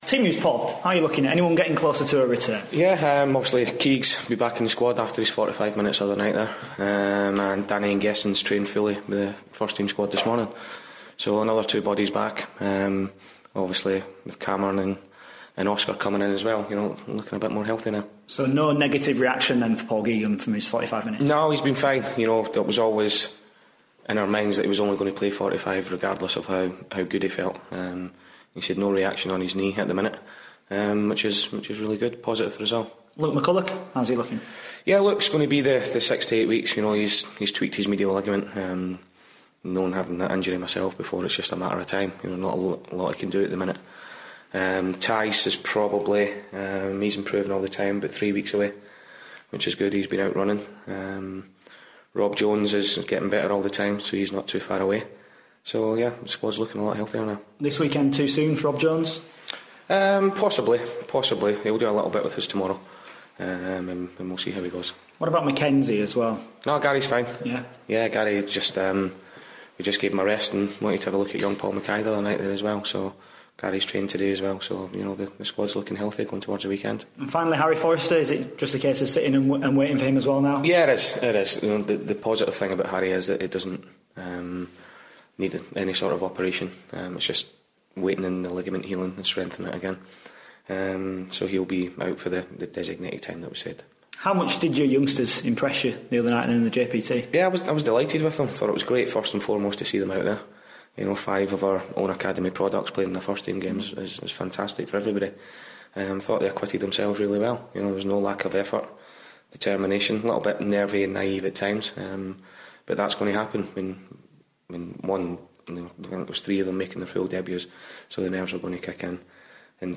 INTERVIEW: Doncaster Rovers manager Paul Dickov ahead of their game at Gillingham